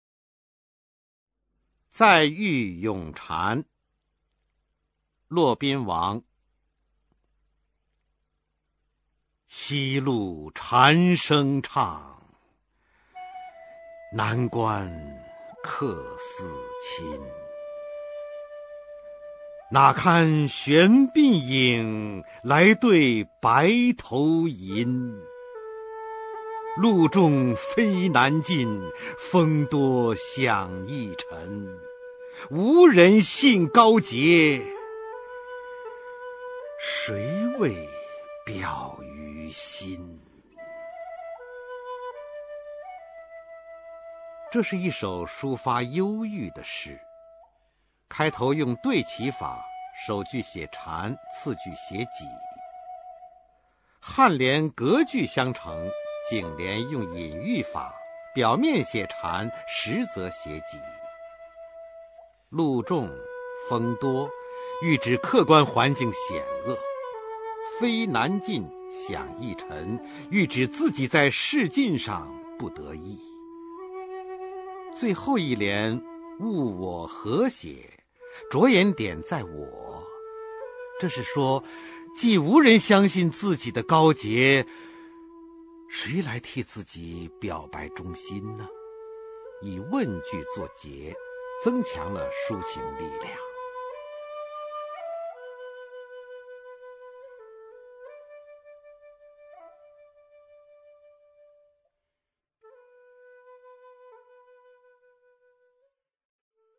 [隋唐诗词诵读]骆宾王-在狱咏蝉·并序 配乐诗朗诵